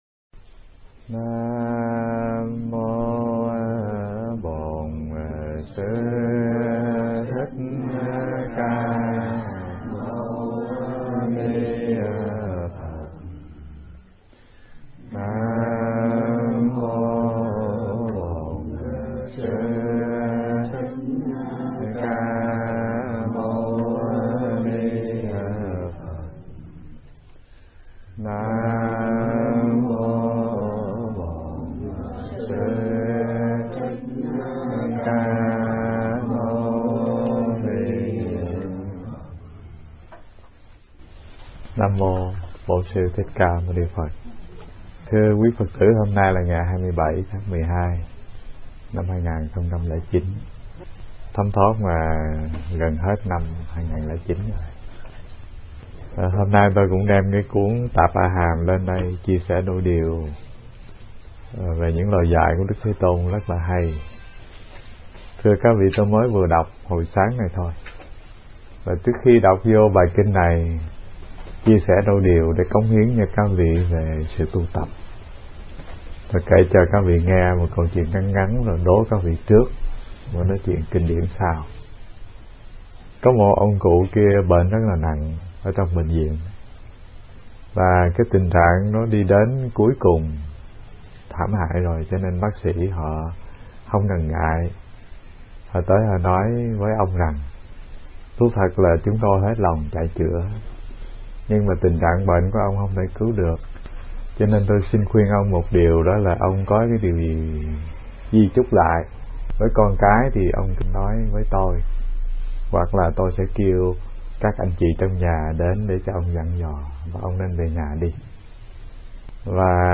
Nghe Mp3 thuyết pháp Điều Phục Bệnh Khổ